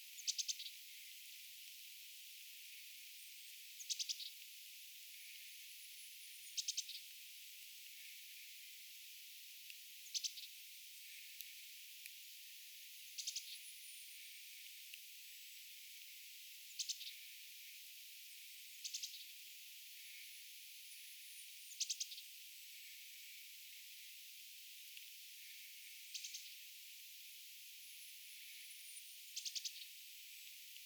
tuollaista_sinitiaislinnun_aantelya.mp3